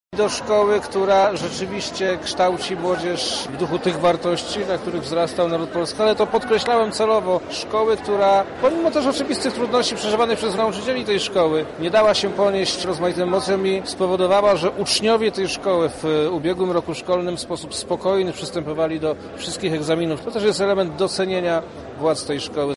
Za nami wojewódzka inauguracja roku szkolnego.
Dlaczego właśnie tam? To tłumaczy Wojewoda Lubelski Przemysław Czarnek: